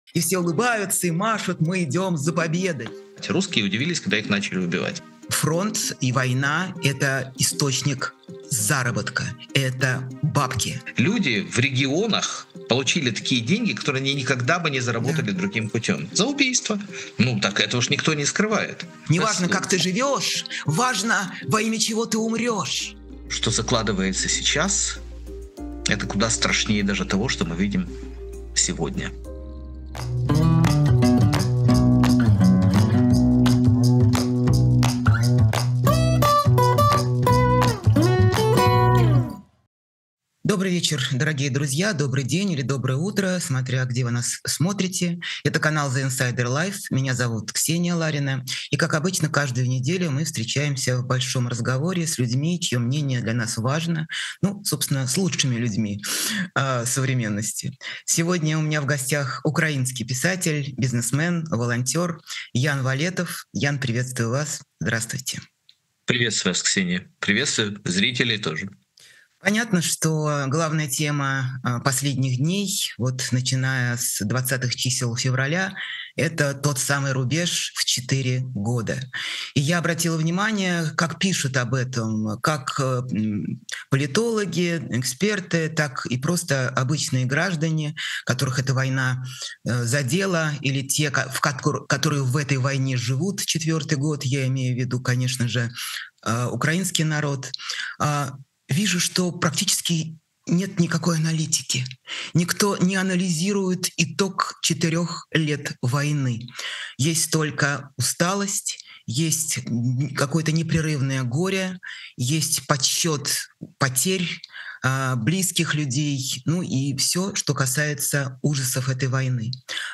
Эфир ведёт Ксения Ларина